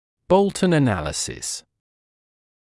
[‘bəultn ə’næləsɪs][‘боултн э’нэлэсис]анализ соответствия размера нижних зубов верхним по методике Болтона